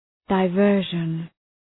Shkrimi fonetik{də’vɜ:rʒən, daı’vɜ:rʒən}